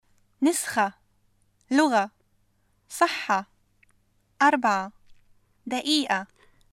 3. ـة の発音は -a か -e か　喉を使って発音する子音（ح،خ،ع،غ،ق،ه،ء  ħ,x,ʕ,ġ,q,h,ʔ ）の後の女性語尾は -a と発音します。
[nəsxa, luġa, Saħħa, ʔarbʕa, daʔiiʔa]